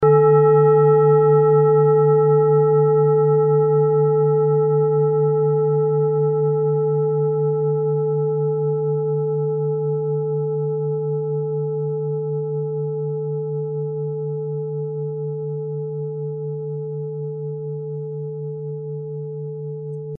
Klangschale TIBET Nr.20
Sie ist neu und ist gezielt nach altem 7-Metalle-Rezept in Handarbeit gezogen und gehämmert worden.
klangschale-tibet-20.mp3